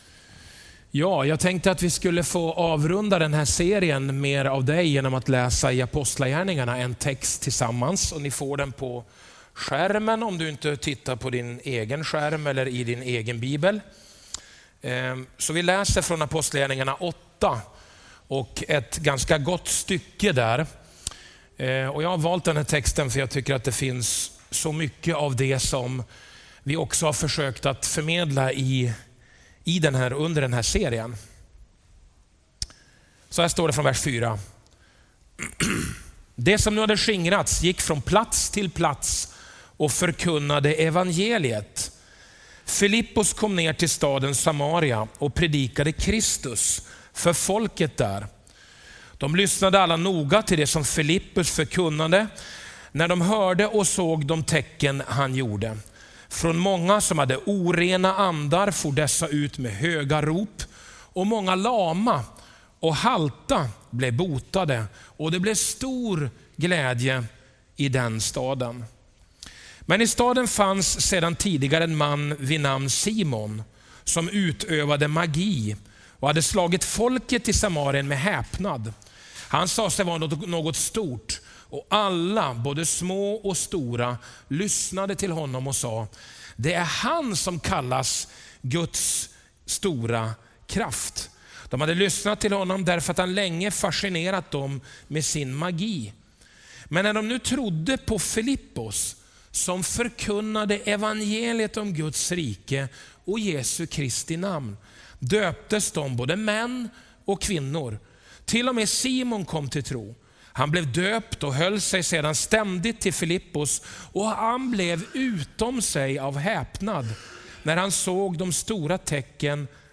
Predikan 17 juni 2018